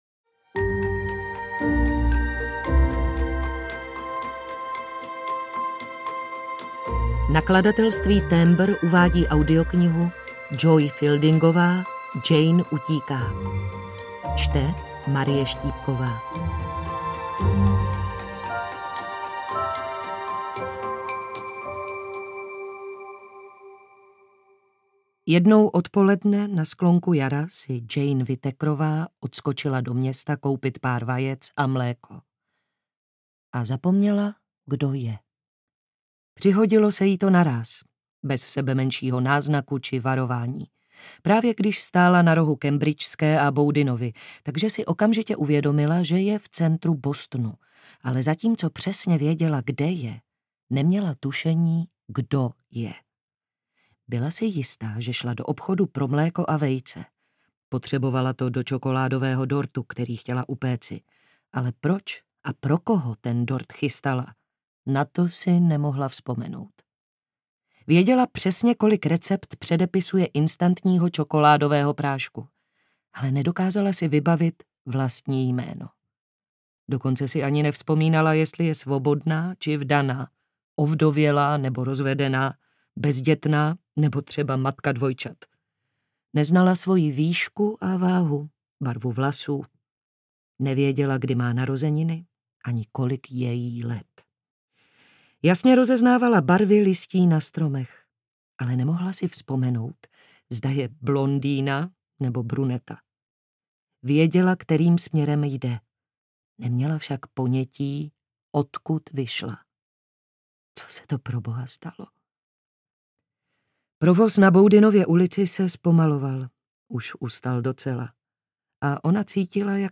Jane utíká audiokniha
Ukázka z knihy